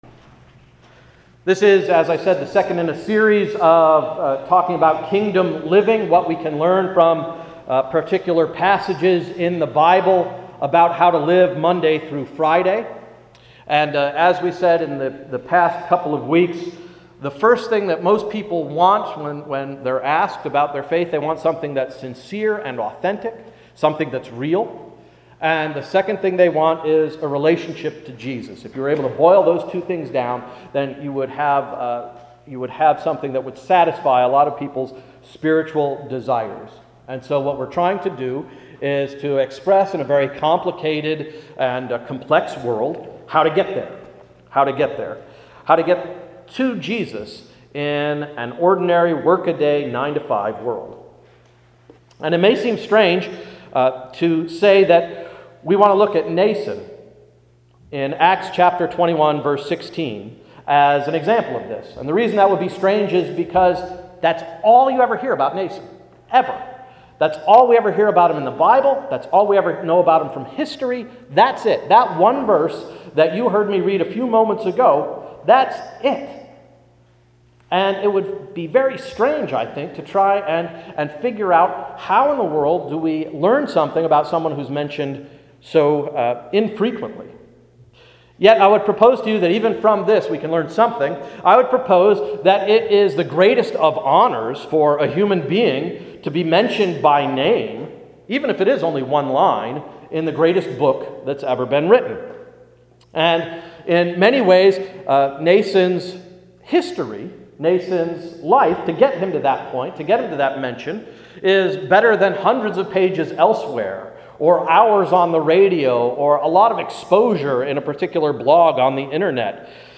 Sermon of March 4, 2012–“Obscurity”